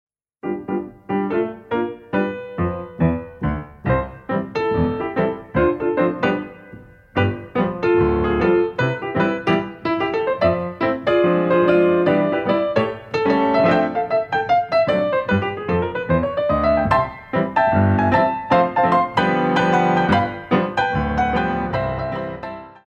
Relevés pointes milieu